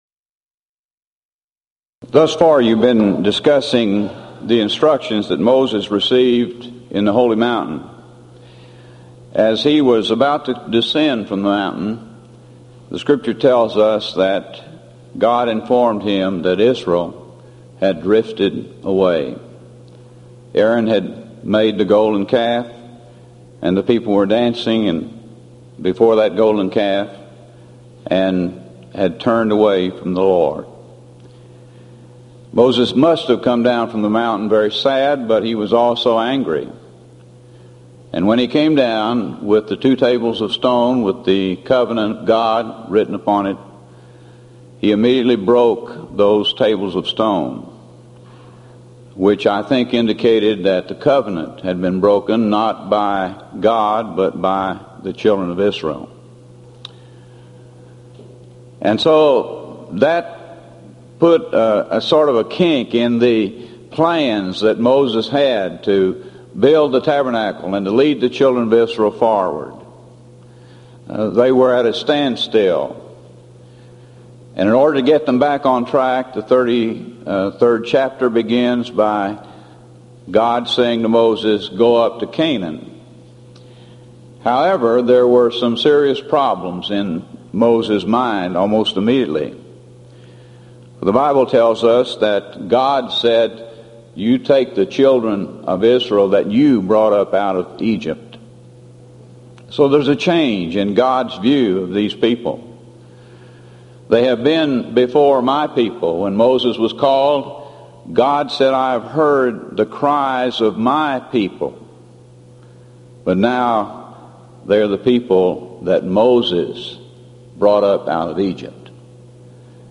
Event: 1997 East Tennessee School of Preaching Lectures Theme/Title: Studies In The Book of Exodus